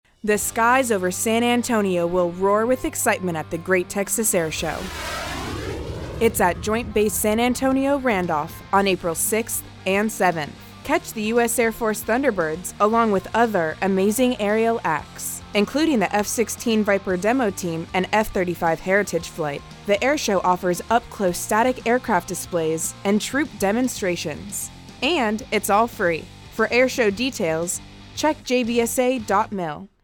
Radio Spot: The Great Texas Airshow 2024 (:30)
Radio spot for the 2024 Great Texas Air Show at Joint Base San Antonio, April 6-7, 2024. This version is in English, 30 seconds, with a music bed.